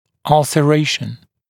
[ˌʌlsə’reɪʃn][ˌалсэ’рэйшн]изъязвление